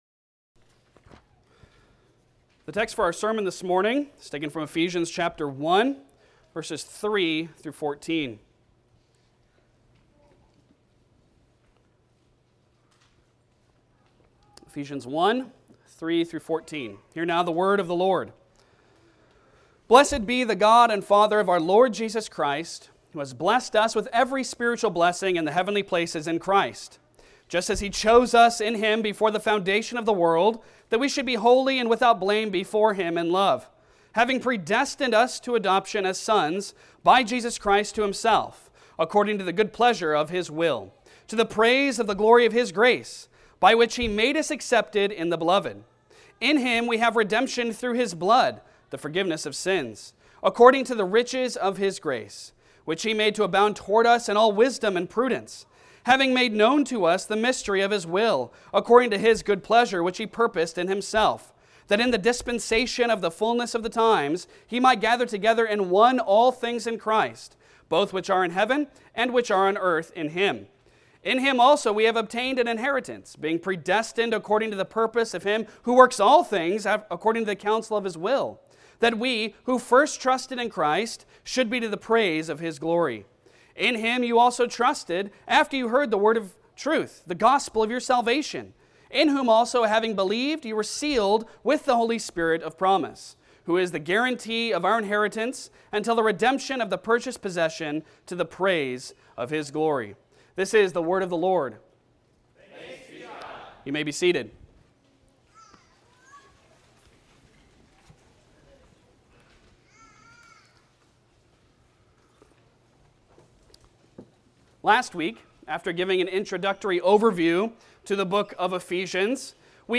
Passage: Ephesians 1:3-14 Service Type: Sunday Sermon